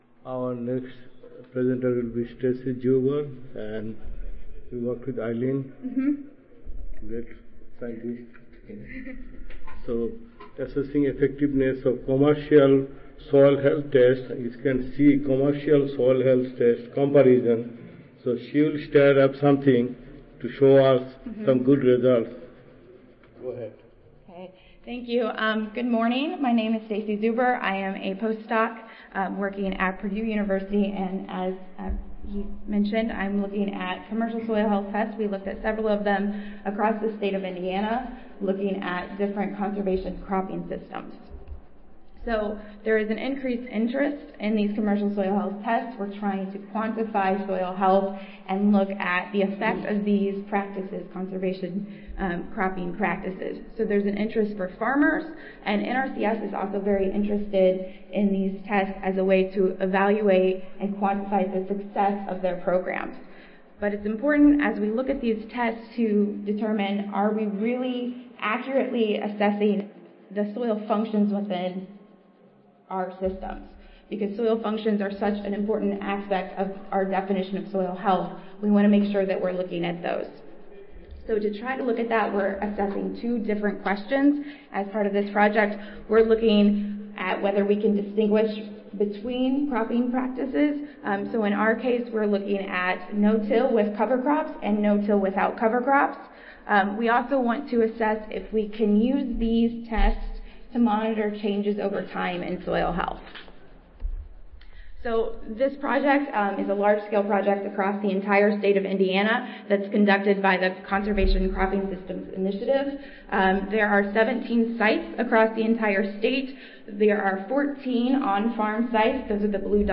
Purdue University Audio File Recorded Presentation